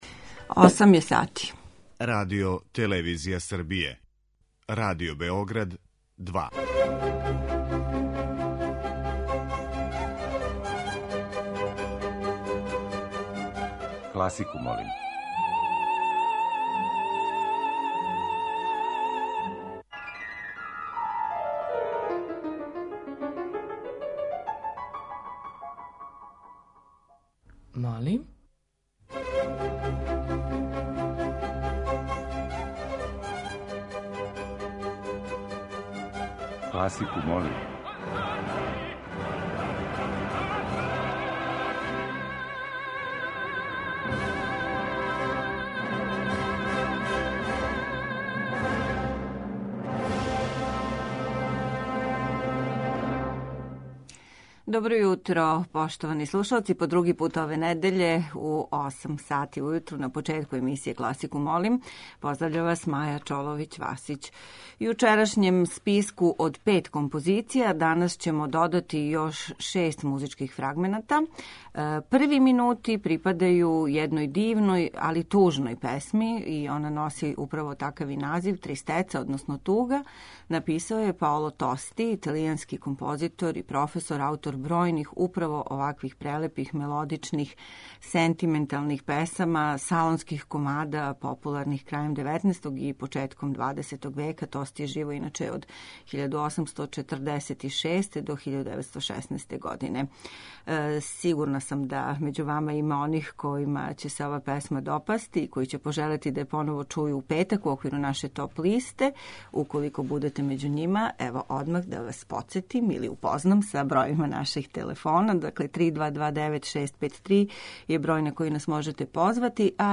Средњевековне и ренесансне игре
Још један избор најразноврснијих музичких фрагмената за хит недеље биће обједињен средњовековним и ренесансним играма.